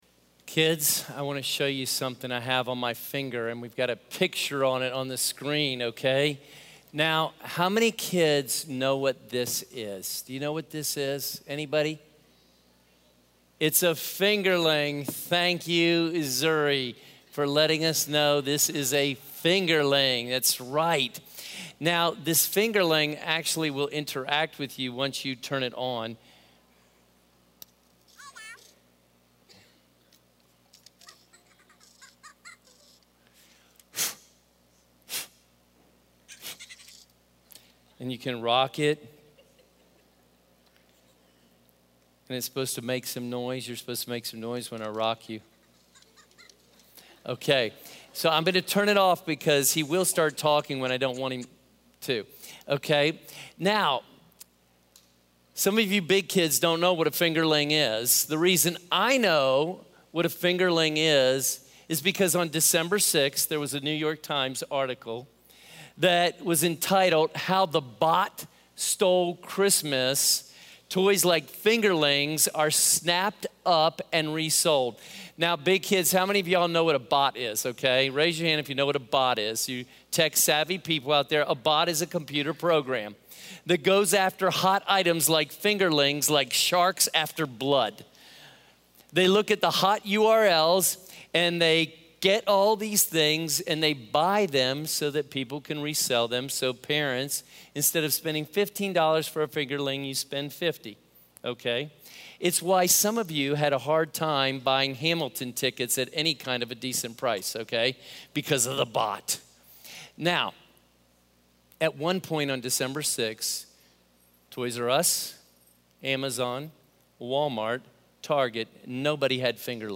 Reflect the Light | Christmas Eve Service